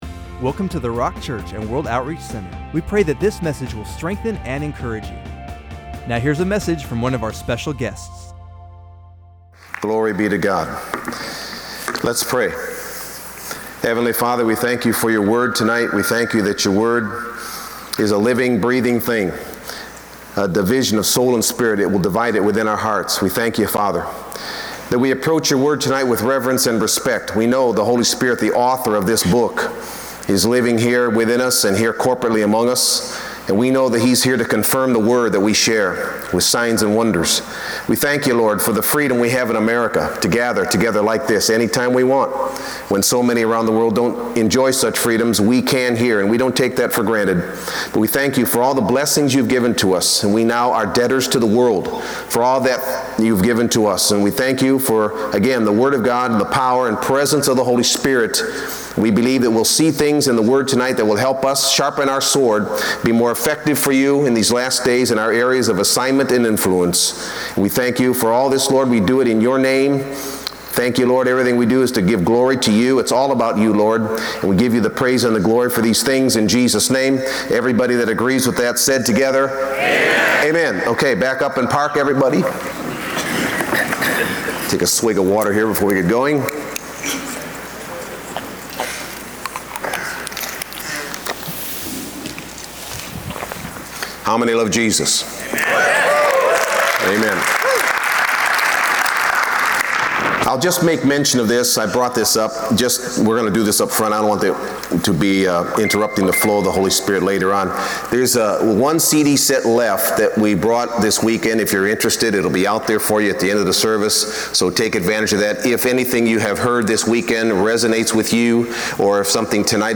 Healing Service | The Rock Church